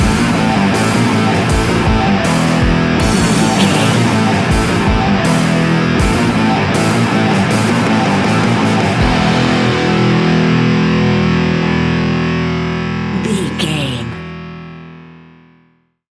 Ionian/Major
hard rock
heavy rock
distortion
instrumentals